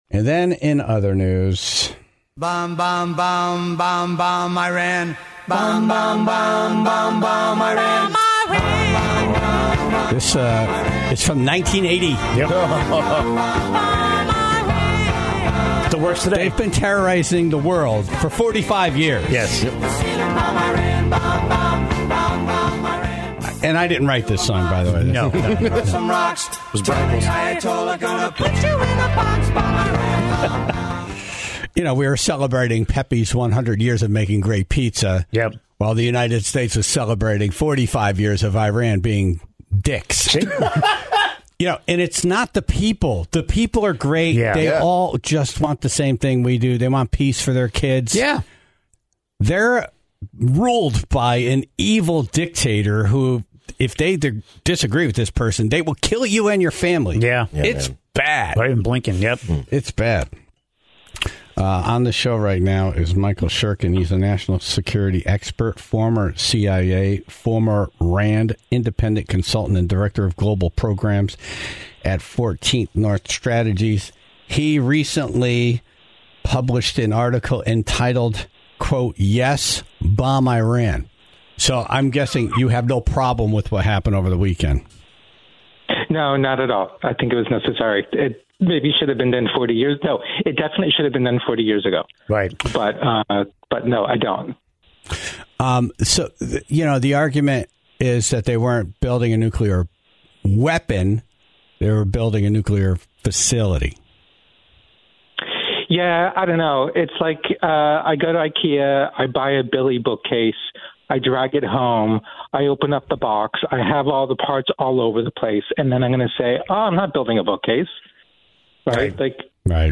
(30:39) Steven Levy, editor at Wired, was on the phone to talk about AI. The latest news seems to indicate that there is evidence that the software is already capable of escaping from human control.